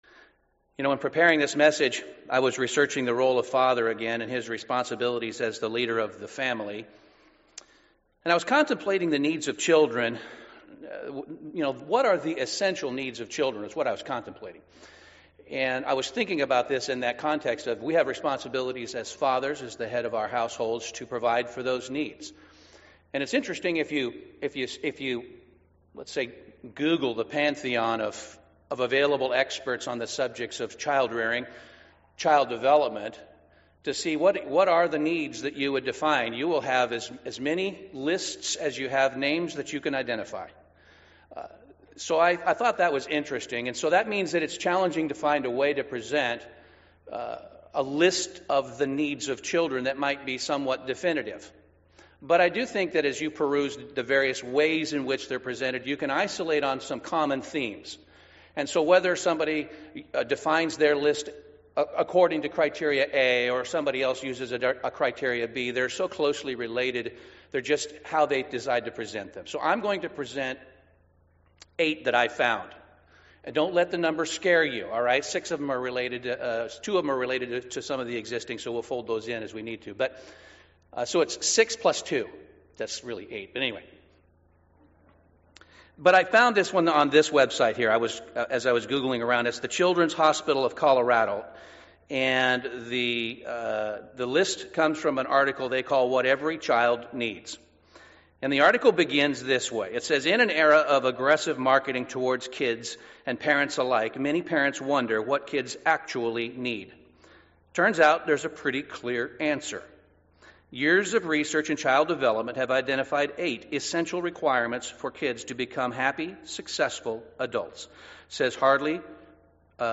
Every child has needs that must be met in order for them to thrive in life. This sermon explores 8 things children need and provides both Biblical and modern research that proves these are essential needs that we must provide our children if we want them to thrive in life.